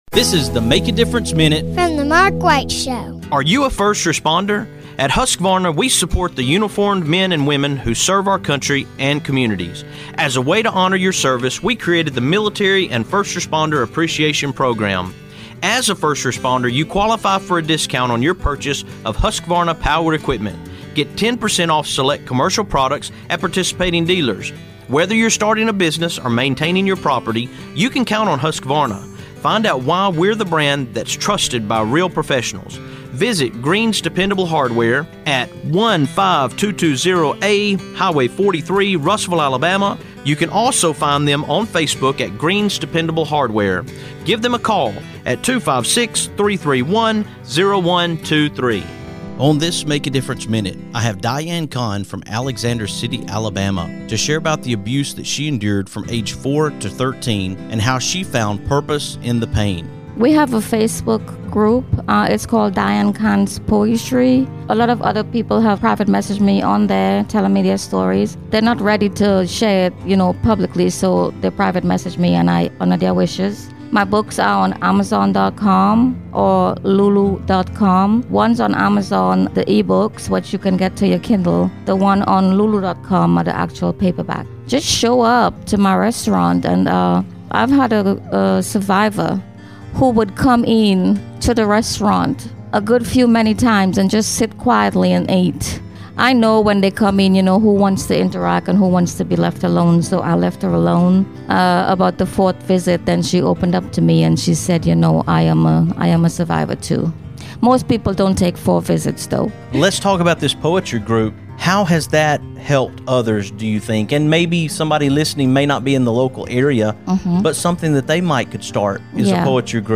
Today, I am releasing an abbreviated version of my conversation